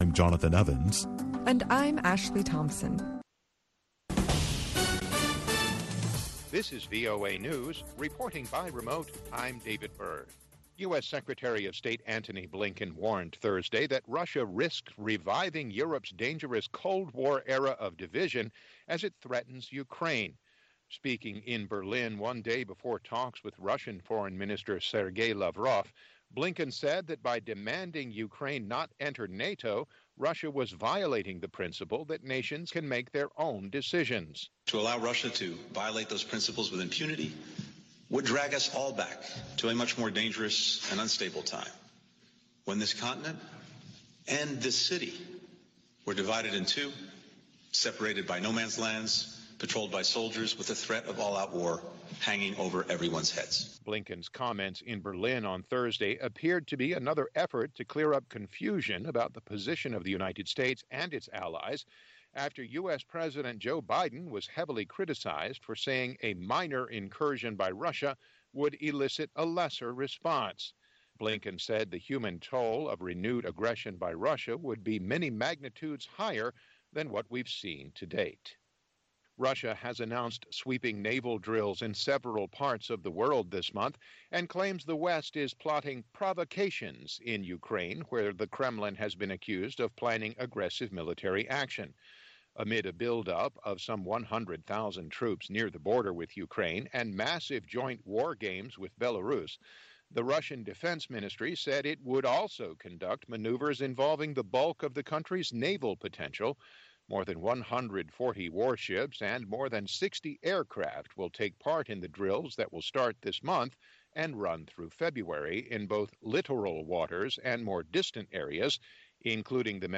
VOA Newscasts